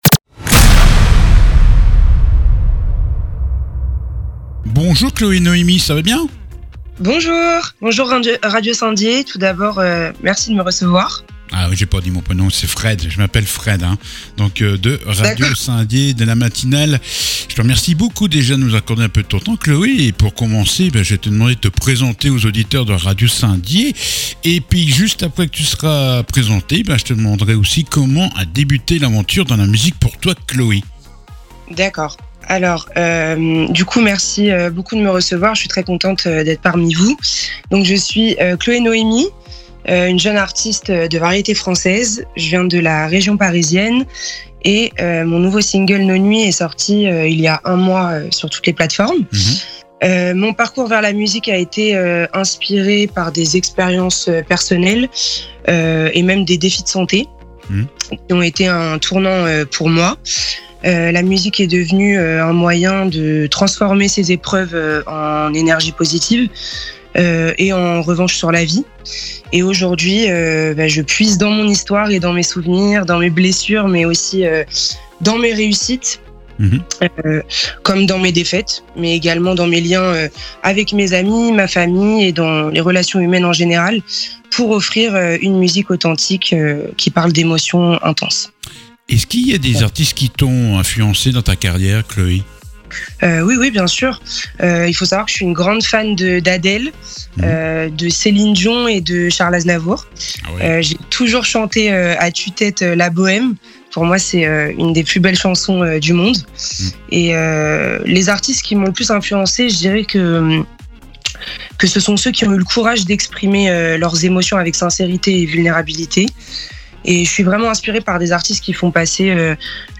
L'interview du jour